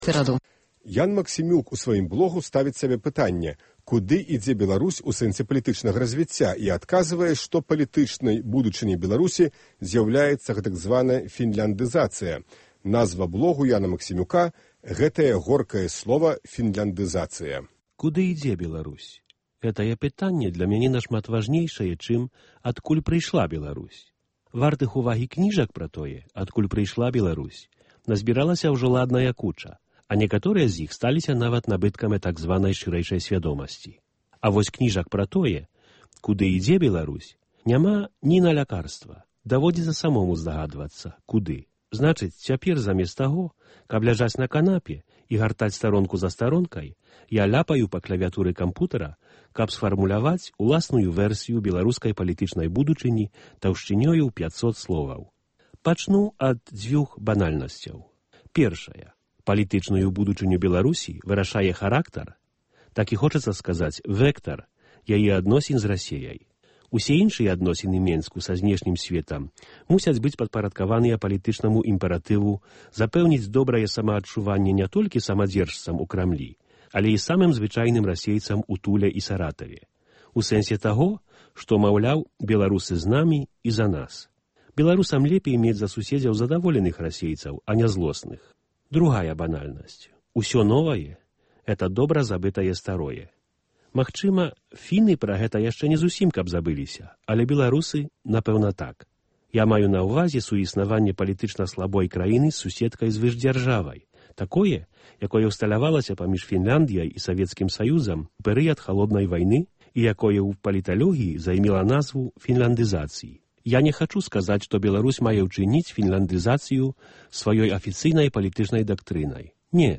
Normal 0 Нашы блогеры чытаюць свае тэксты